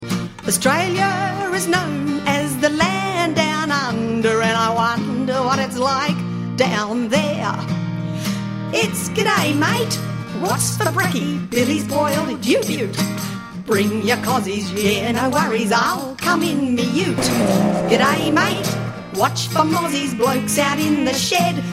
Australian Singer/Songwriter